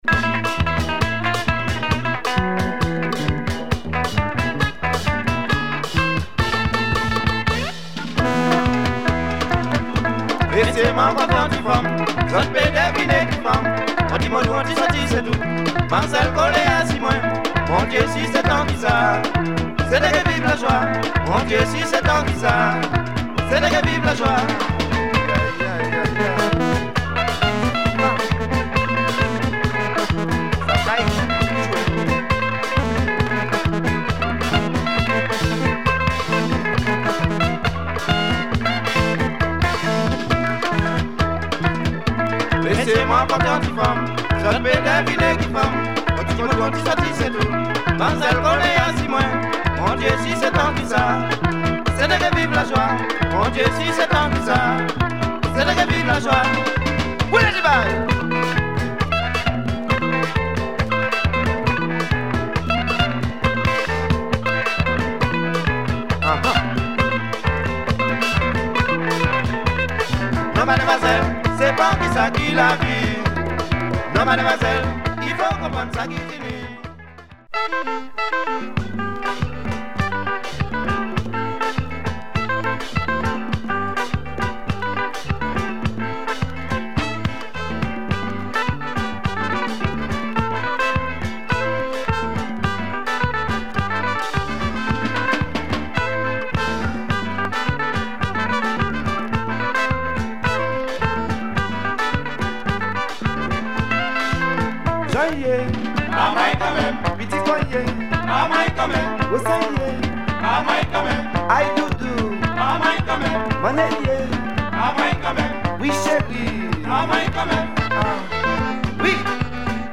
Cadence and kompa.